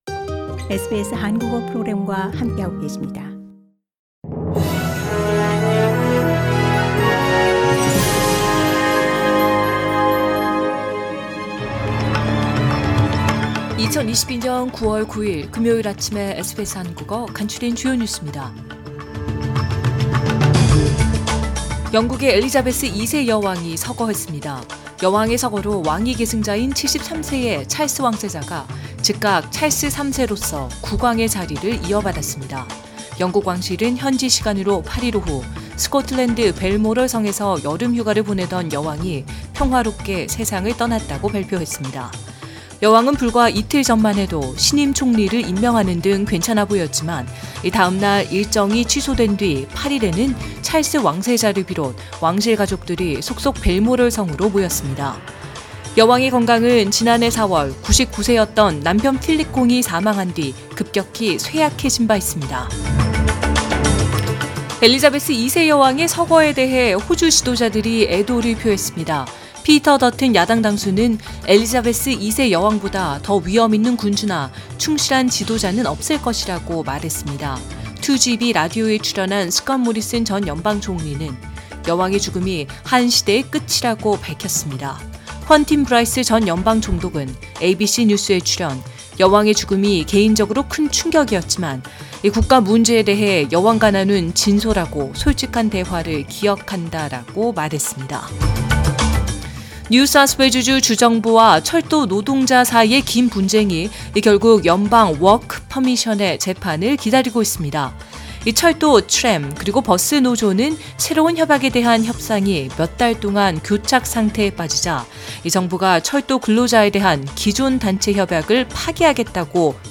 SBS 한국어 아침 뉴스: 2022년 9월 9일 금요일